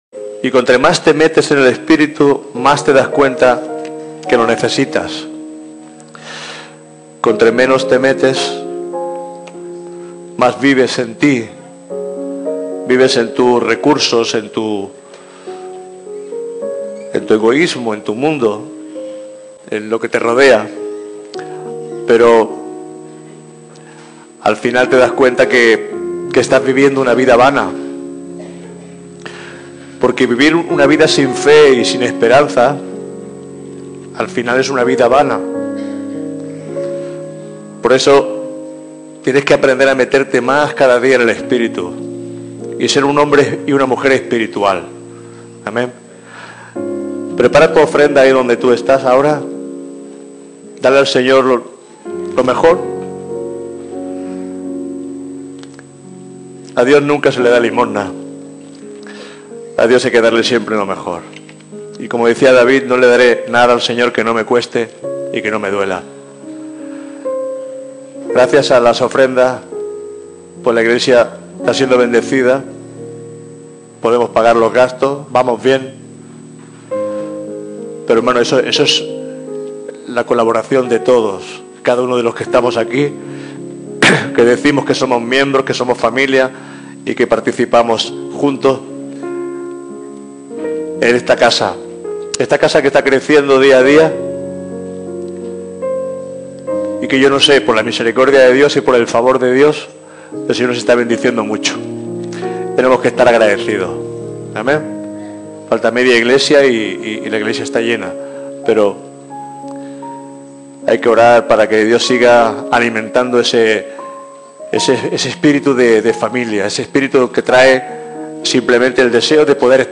Sermó religiós i petició d'almoines